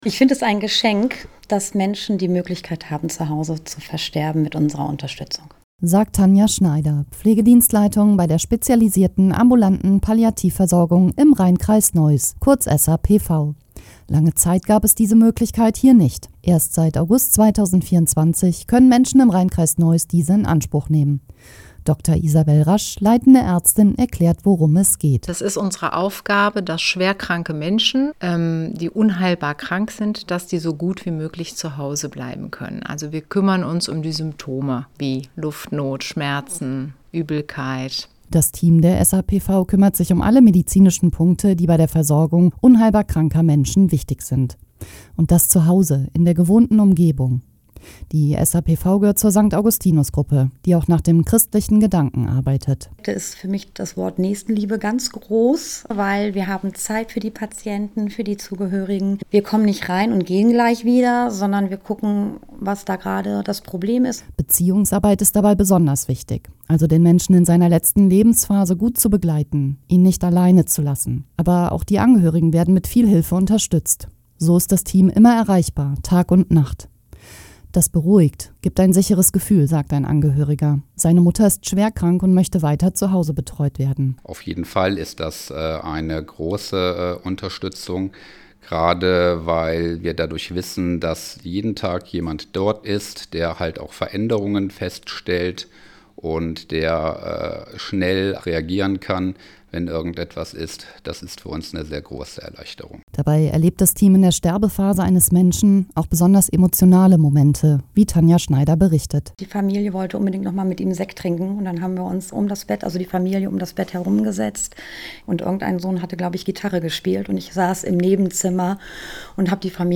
Was Nähe wirklich bedeutet – die SAPV im Radio